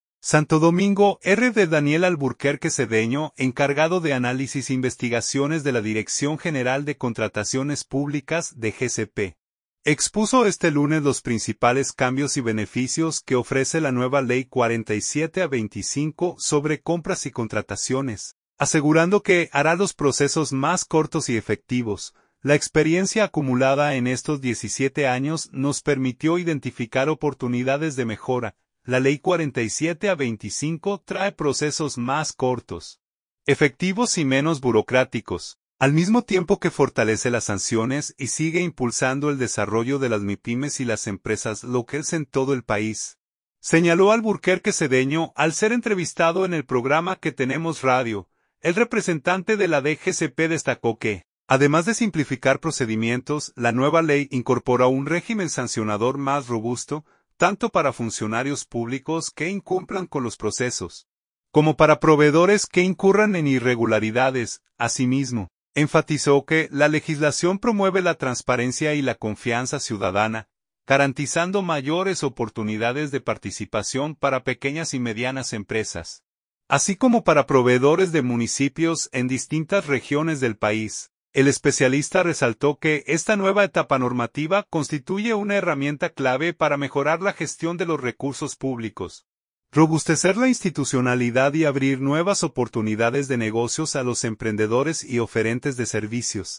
al ser entrevistado en el programa “Qué tenemos radio”